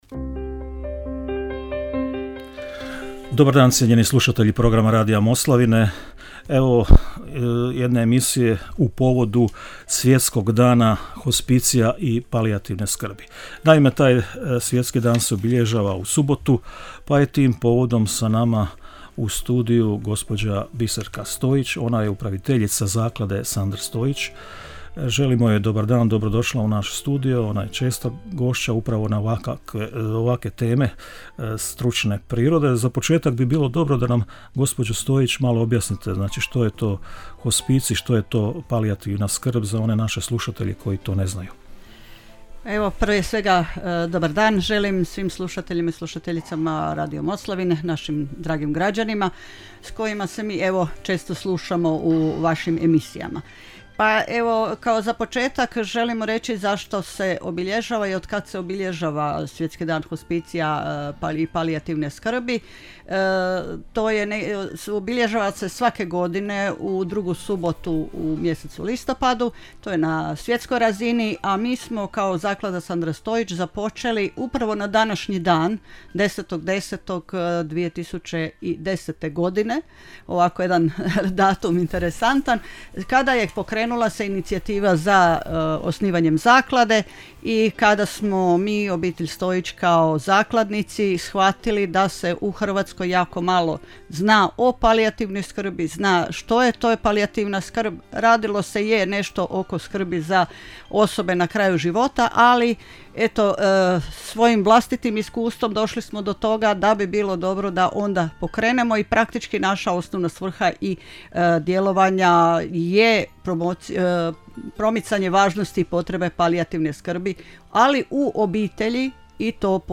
Radio emisija o palijativnoj skrbi.mp3